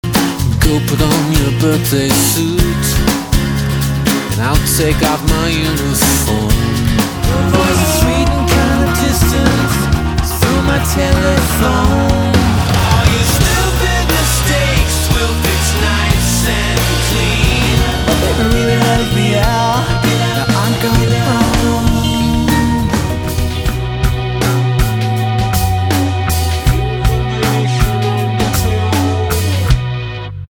Like a beautiful sampler mix machine:
Is there more of a commercial sound on this record?
artsy California stoner-rock isn’t my usual thing but damn